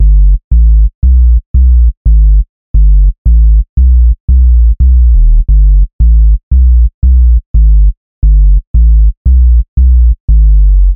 DNB循环 175 Bpm
Tag: 175 bpm Drum And Bass Loops Drum Loops 2.77 MB wav Key : Unknown